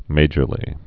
(mājər-lē)